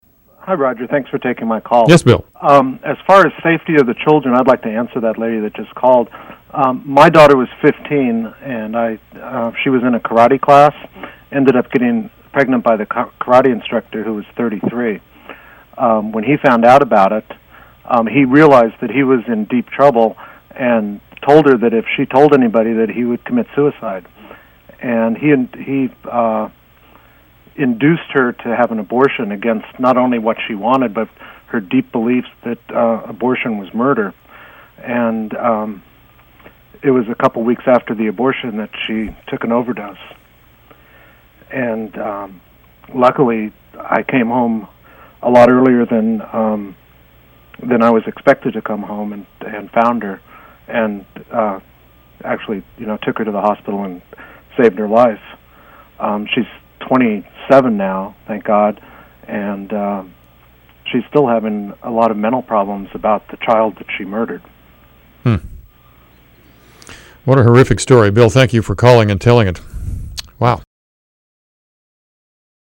AFathersTestimony.mp3